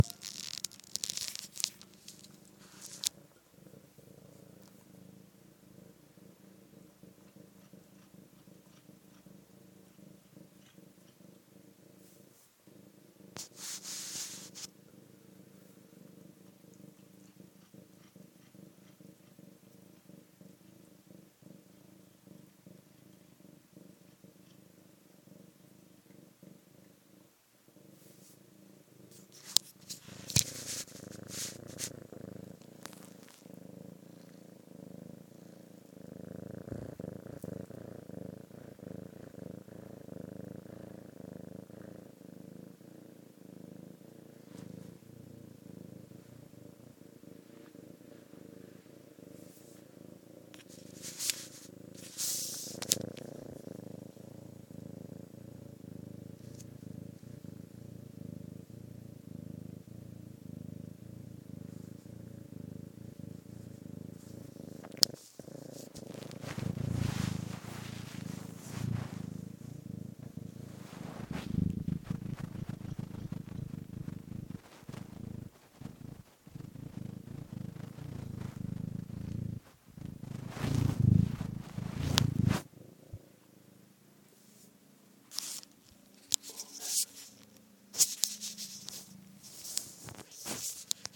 Cat purring